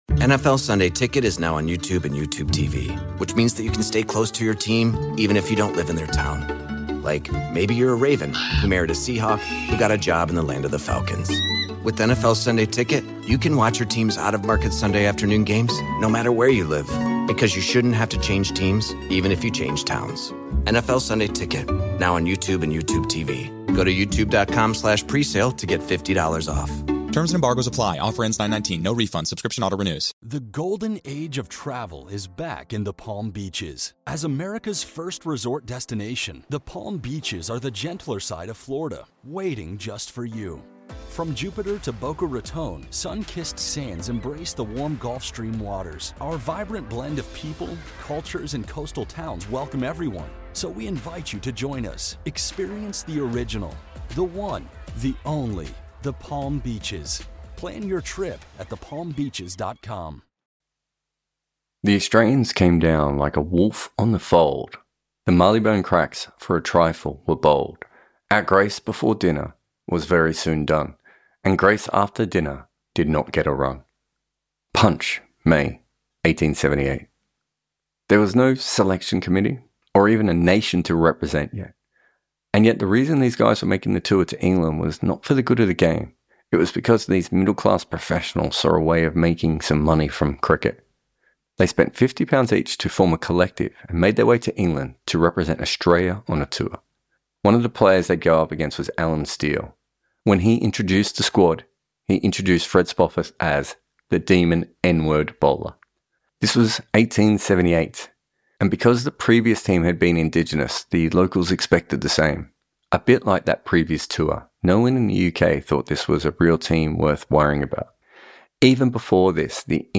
wrote and narrated this episode